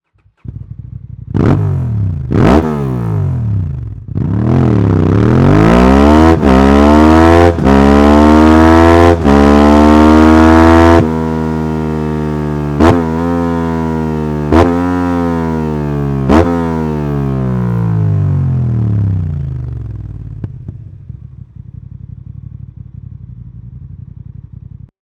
Der Optional Header erzeugt einen tiefen, rennsportlichen Sound, der den Zweizylindermotor vollständig umschließt, und lässt sich einfach per Plug & Play installieren.
Geräusch 99.7 dB (+5.2 dB) bei 4750 U/min
Sound Akrapovic Komplettanlage Honda XL 750 Transalp